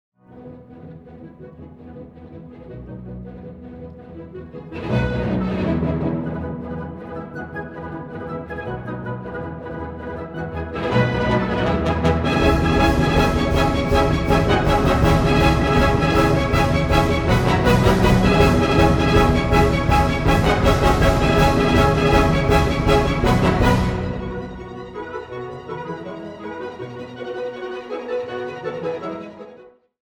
Atlanta Symphony Youth Orchestra Finale Concert